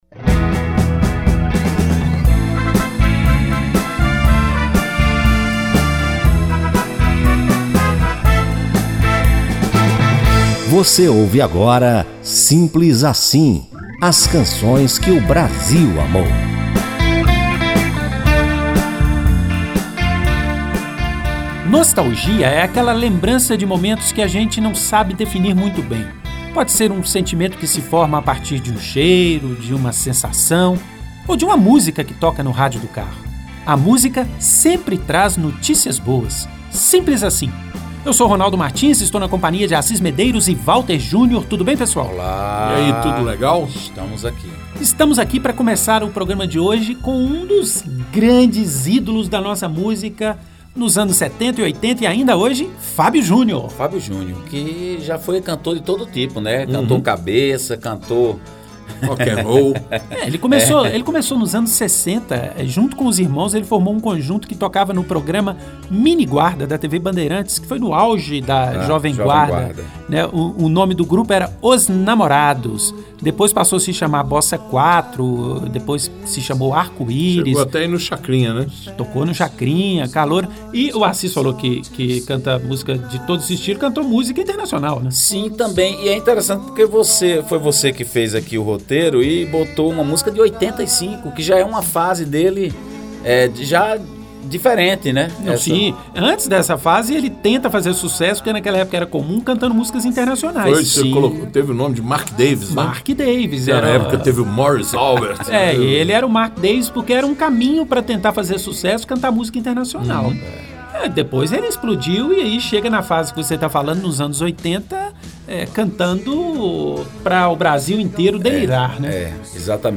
cantores dos anos 80